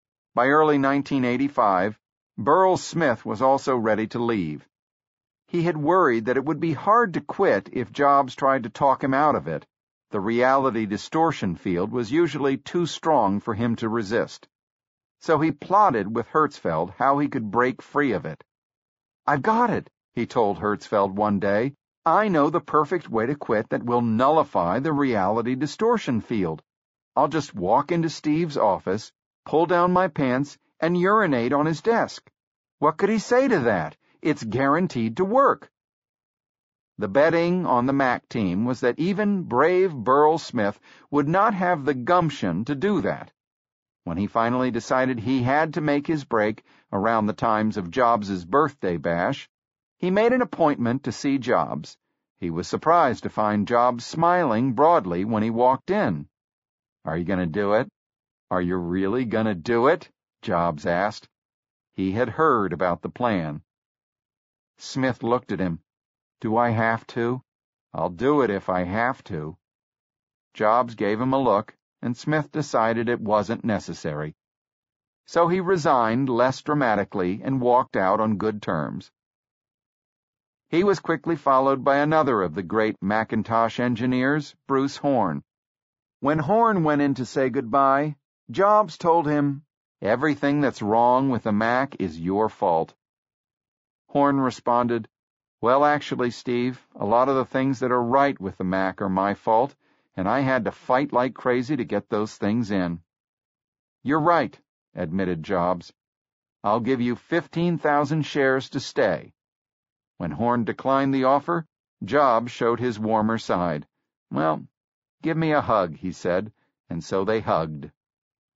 在线英语听力室乔布斯传 第182期:出埃及记(2)的听力文件下载,《乔布斯传》双语有声读物栏目，通过英语音频MP3和中英双语字幕，来帮助英语学习者提高英语听说能力。
本栏目纯正的英语发音，以及完整的传记内容，详细描述了乔布斯的一生，是学习英语的必备材料。